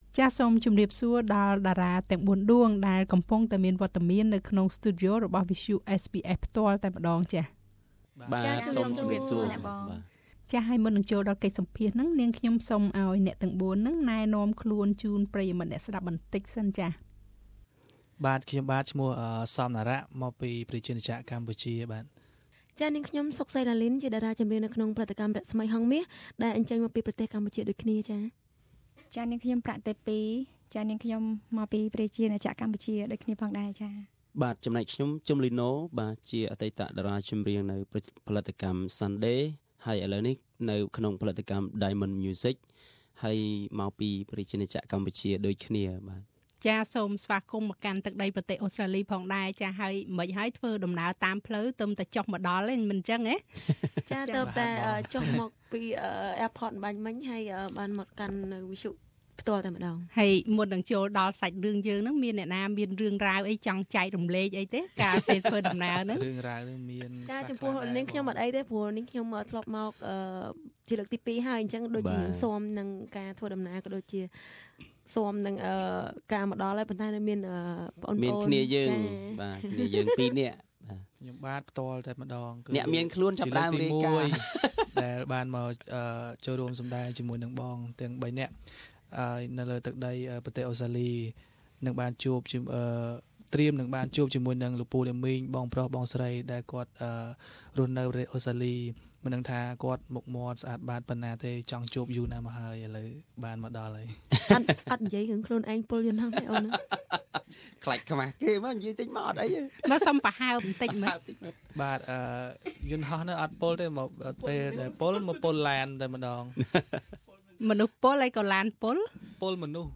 Four Khmer Pop singers, who have just landed in Australia, share their experience with SBS Khmer.